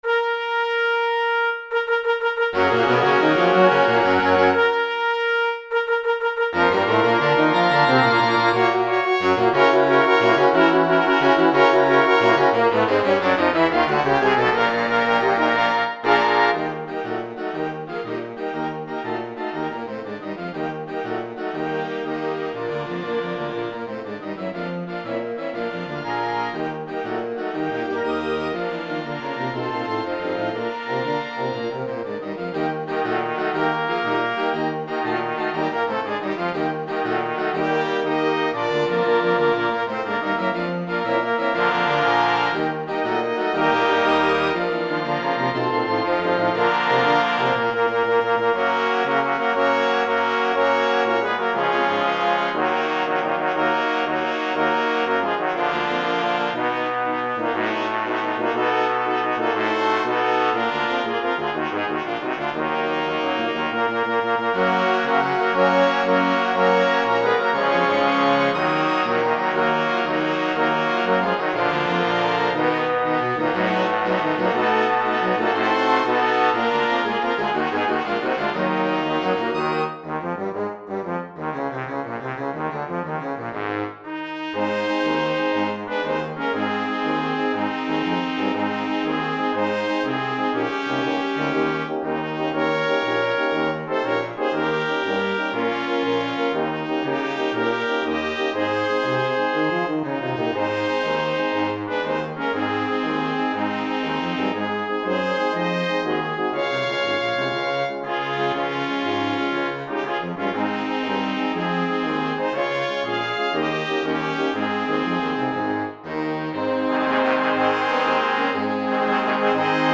I am redoing the march with the FINALE Composition program.
MARCH MUSIC